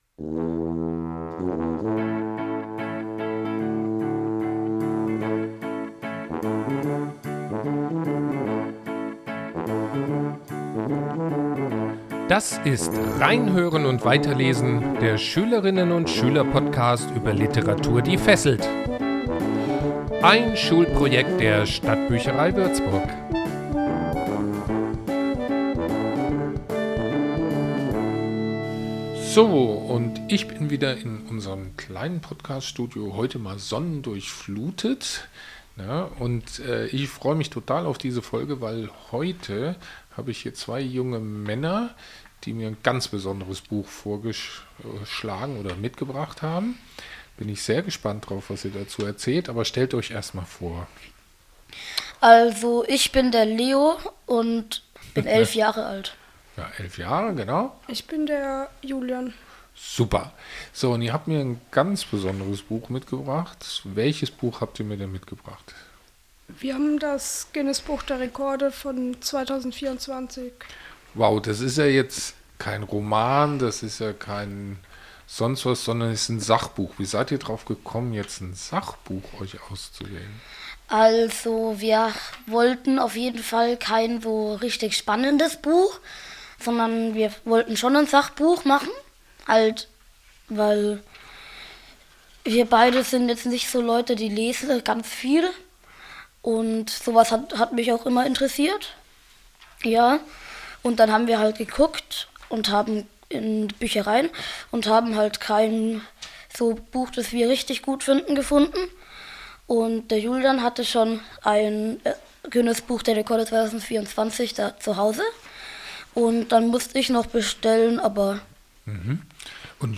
Eine interessantes Gespräch über ein sehr eigenes Sachbuch.